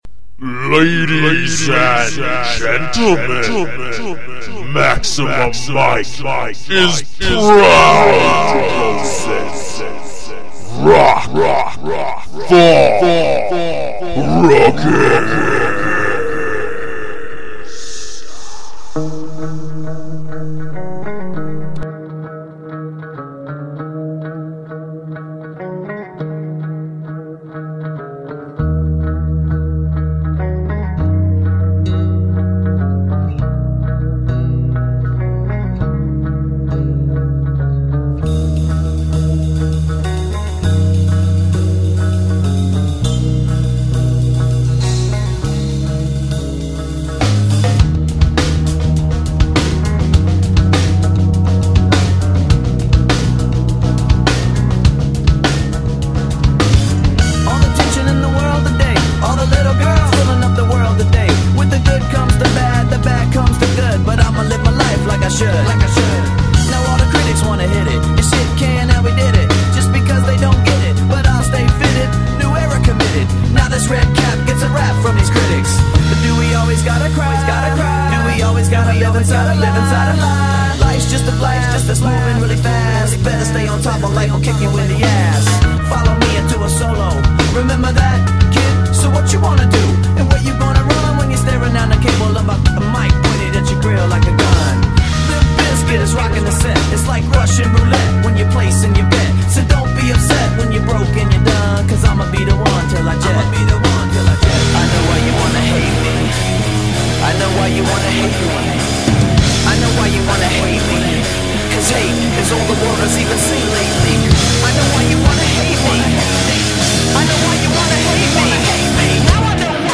A block chocked full of Rock.
And Indie rock so weird you don’t want to know the names of the bands.